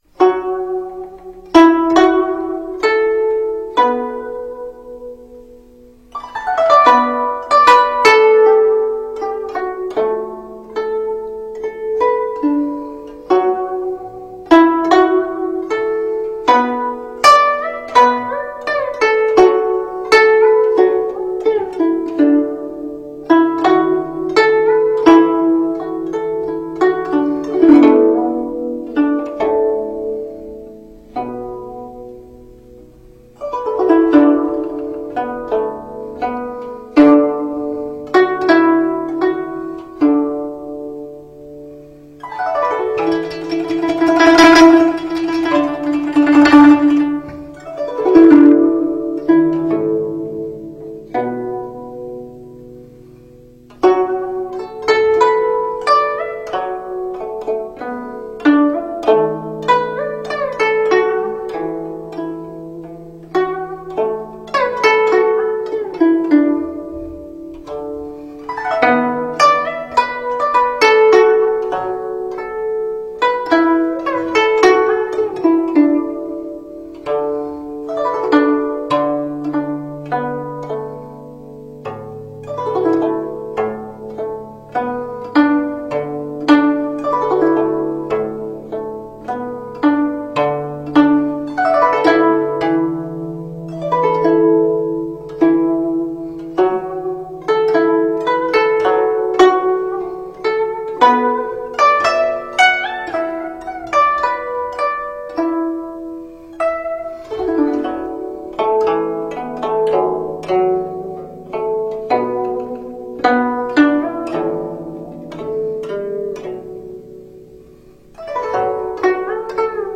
《高山流水》--古筝
《高山流水》--古筝 冥想 《高山流水》--古筝 点我： 标签: 佛音 冥想 佛教音乐 返回列表 上一篇： 渔樵问答--古琴 下一篇： 梁祝--古筝 相关文章 蝶花--桑吉平措 蝶花--桑吉平措...